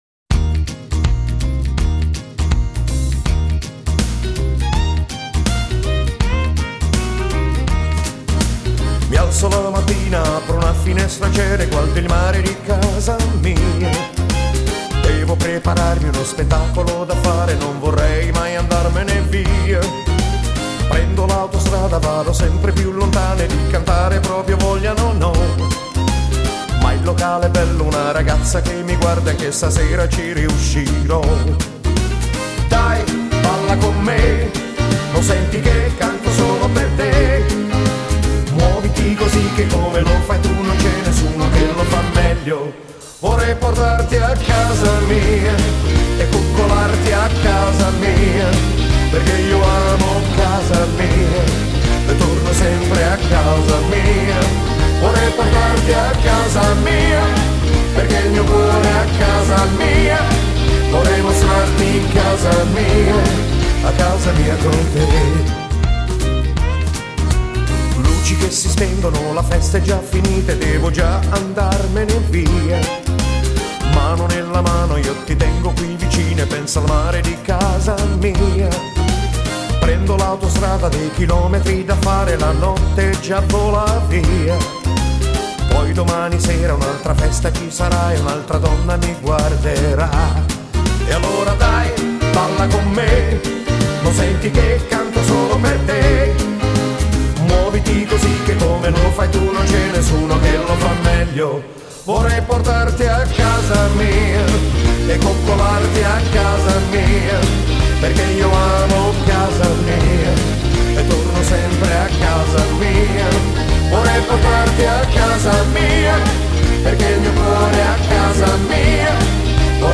Genere: Rock and roll